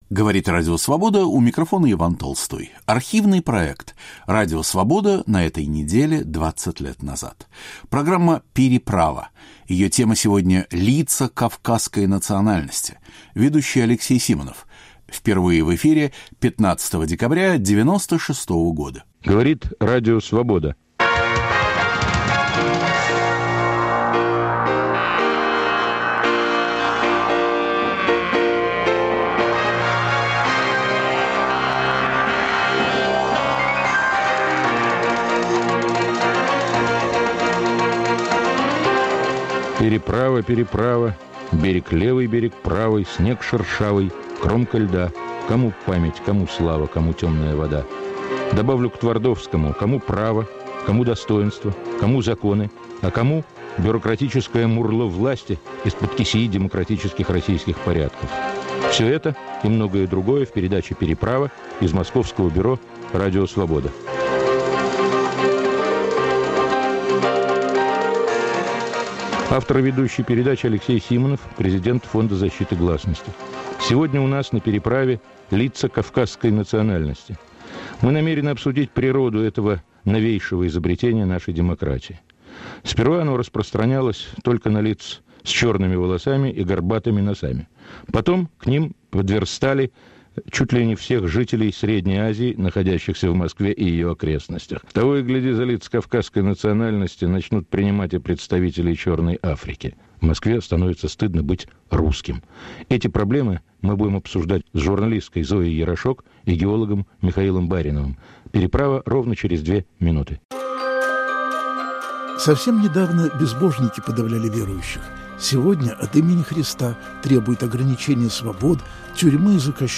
Журналисты, юристы и правозащитники о ксенофобии в России. Впервые в эфире 15 декабря 1996.